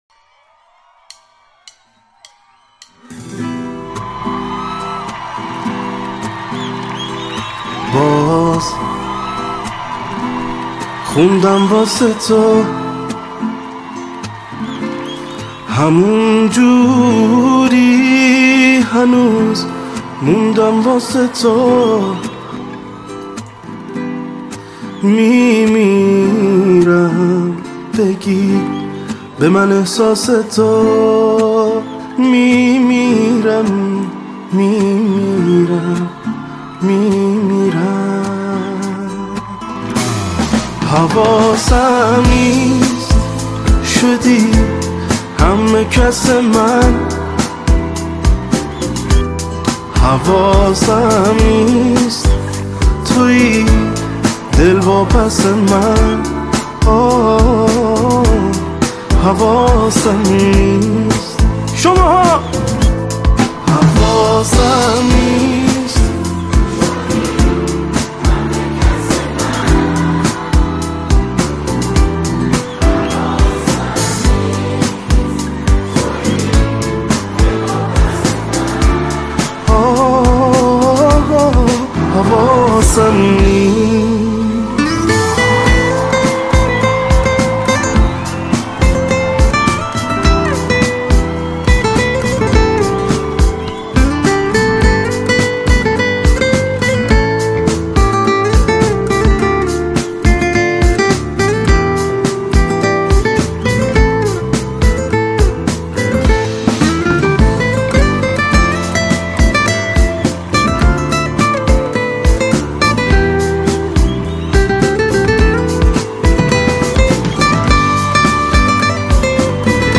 “اجرای زنده”